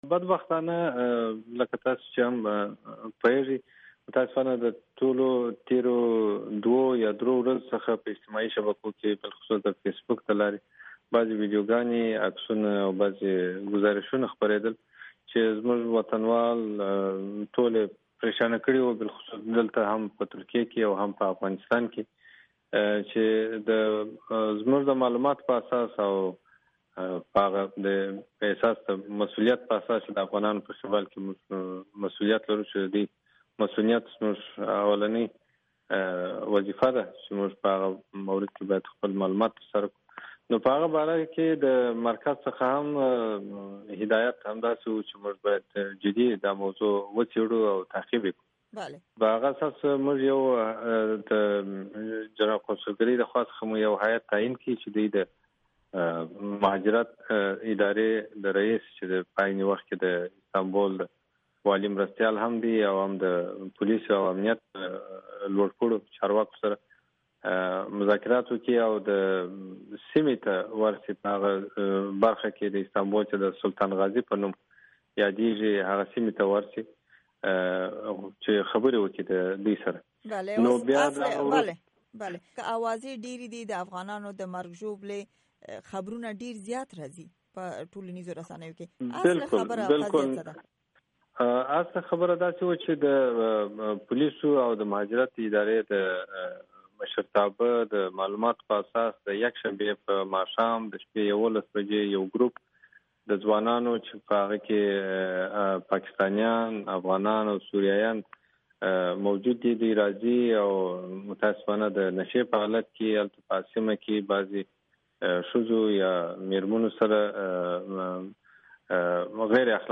په استنبول کې د افغانستان جنرال قونصل عبدالملک قریشي سره مرکه